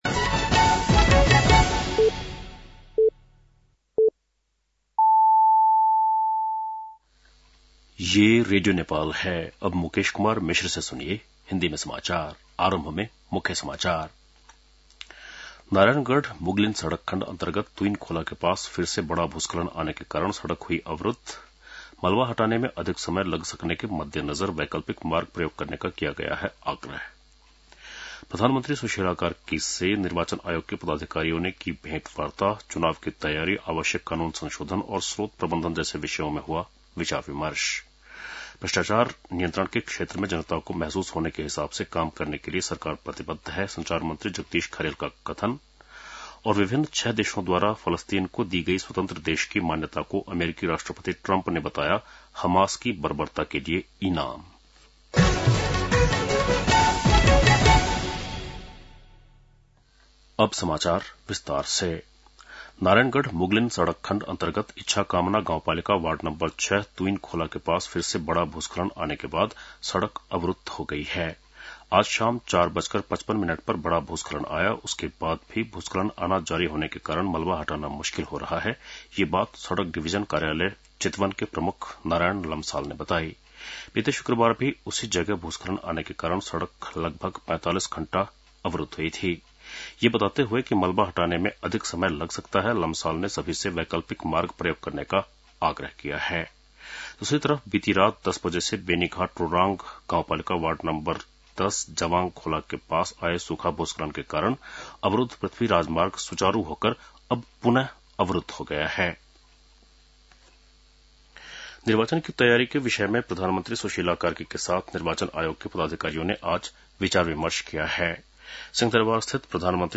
बेलुकी १० बजेको हिन्दी समाचार : ७ असोज , २०८२
10-pm-hindi-news-6-07.mp3